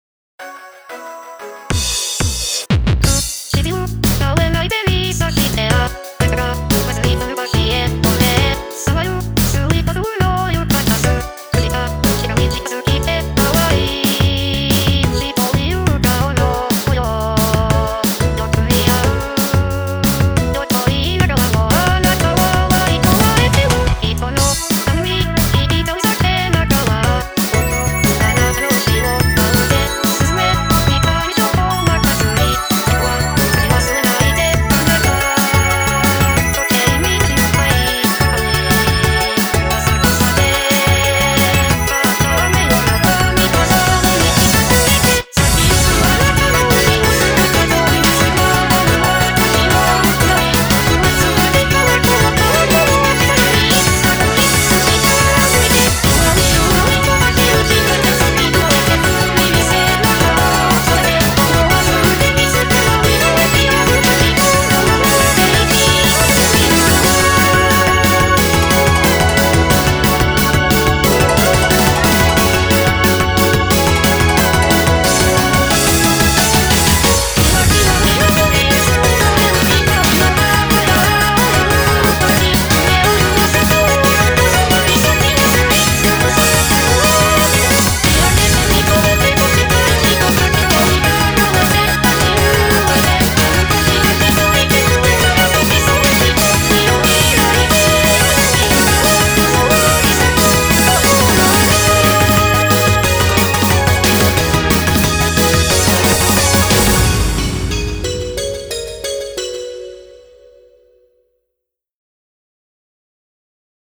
BPM180